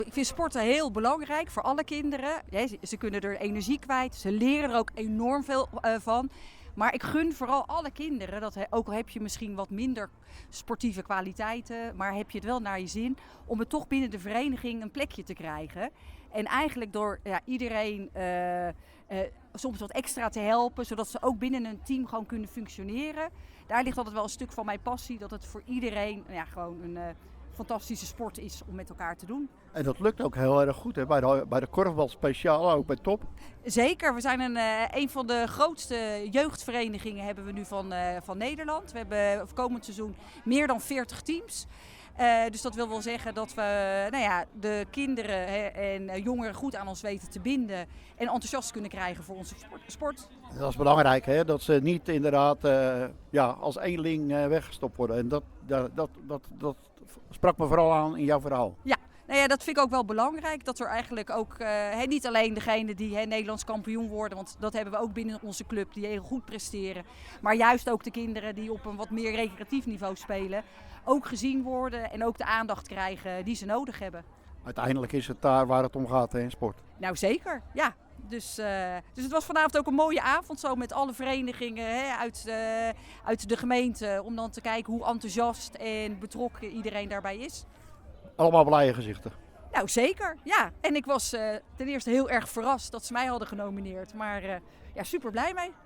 Teylingen – Tijdens het Sportgala Teylingen zijn donderdagavond de jaarlijkse sportprijzen uitgereikt.